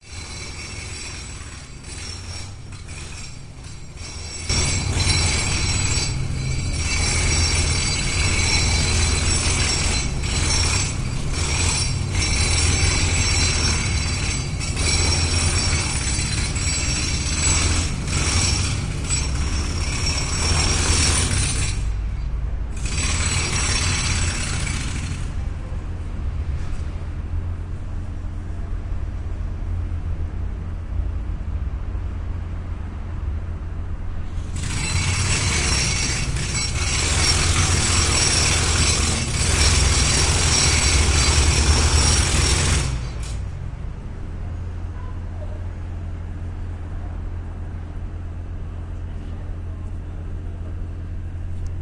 现场记录 " 海底钻探
描述：一艘大型驳船钻进岩石海床，用于建造一座桥梁。声音非常响亮，并且通过水很好地运转，将我的帆船变成了共鸣室。距离驳船100米处，我将索尼PCMM10放入舱底并进行录制。请享用！
标签： 建造 建设者 锤击 建设 钻井 工程 无人机 工作噪音小 海底 矿山 桥梁 建筑
声道立体声